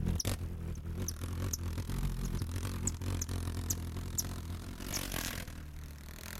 3098b9f051 Divergent / mods / Soundscape Overhaul / gamedata / sounds / ambient / soundscape / swamp / sfx_2.ogg 198 KiB (Stored with Git LFS) Raw History